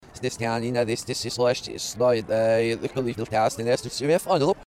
Can you guess the footballing voice?